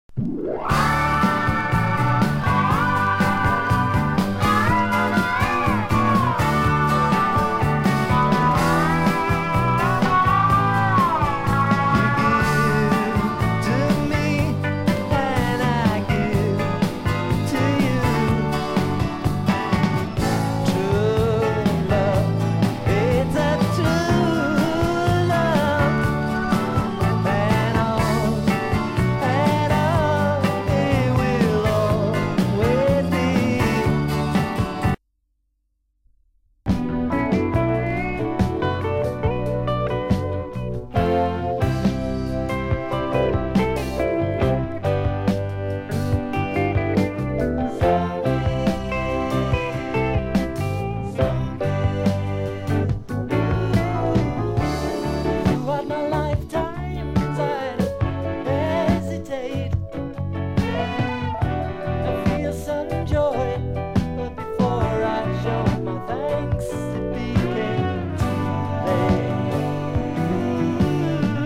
フェンダーとホーンを導入したアレンジがすごくオシャレ。